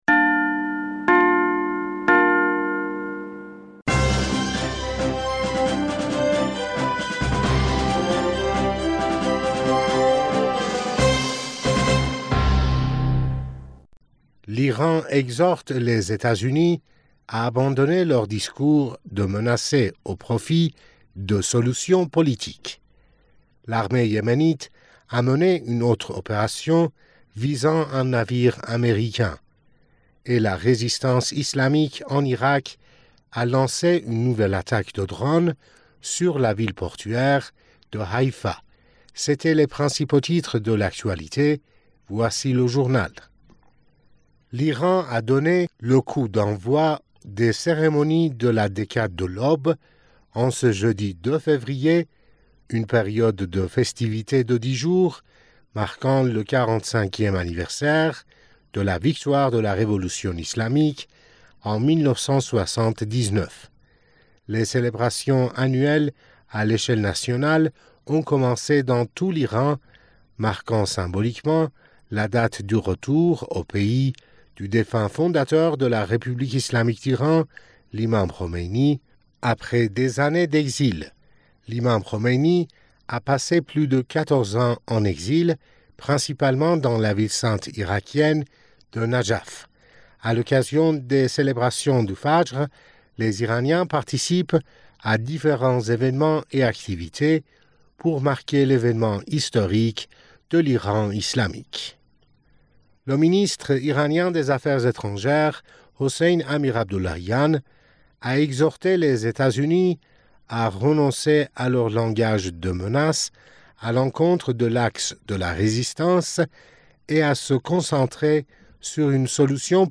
Bulletin d'information du 01 Fevrier 2024